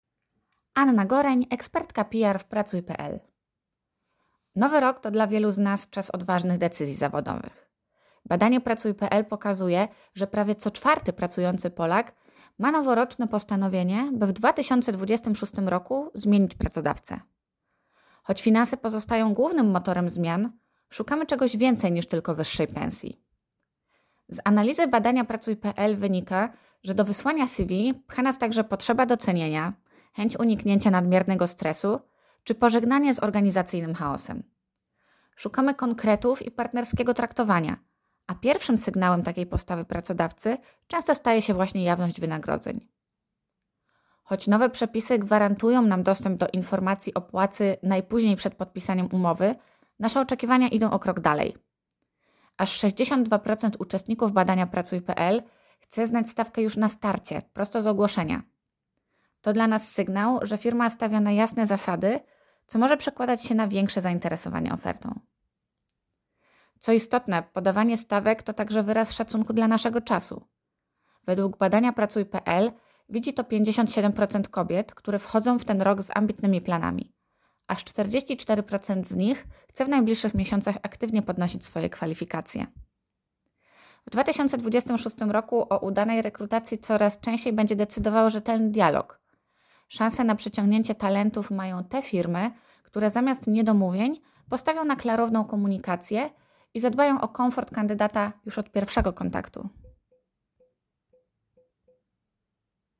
Skorzystaj z komentarza AUDIO